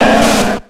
Cri d'Ortide dans Pokémon X et Y.